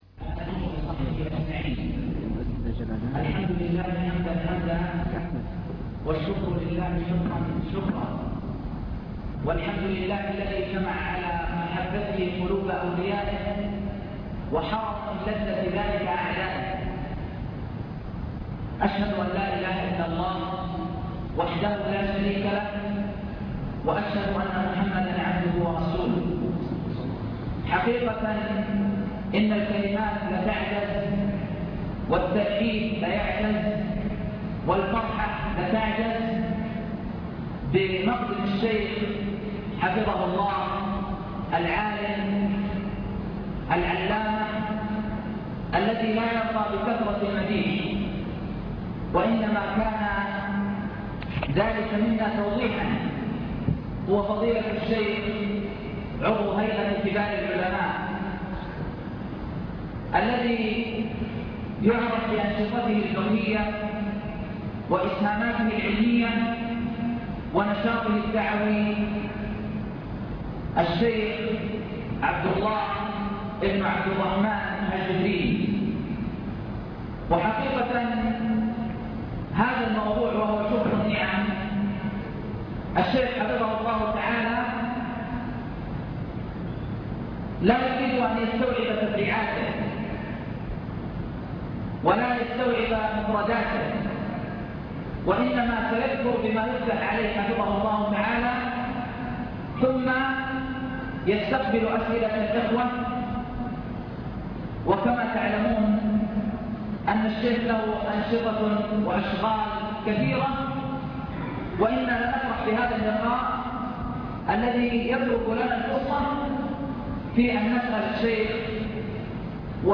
المكتبة الصوتية  تسجيلات - محاضرات ودروس  محاضرة بعنوان شكر النعم (1)
تقديم